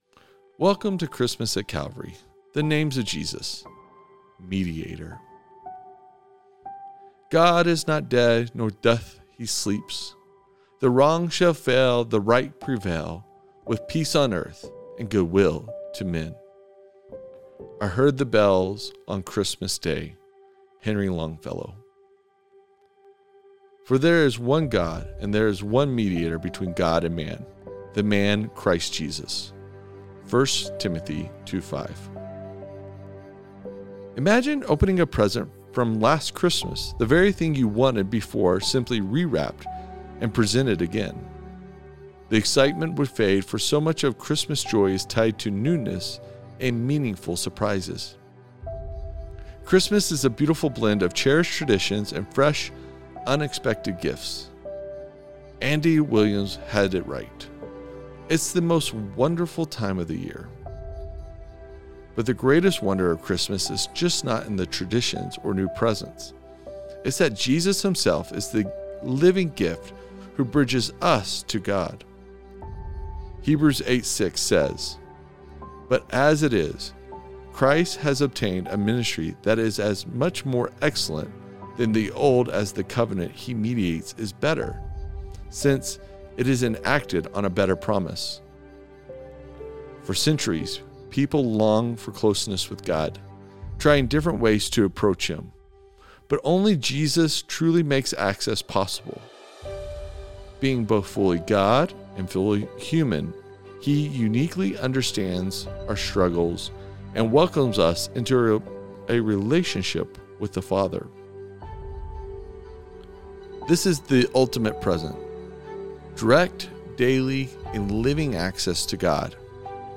Advent Readings & Prayer